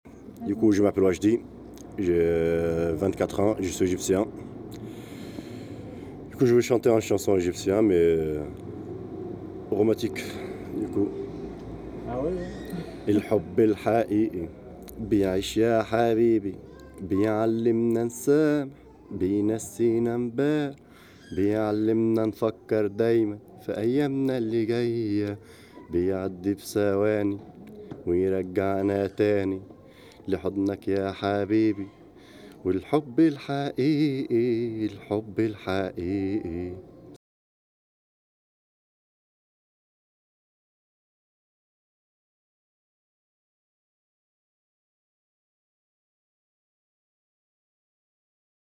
Les sonoportraits
chant égyptien en arabe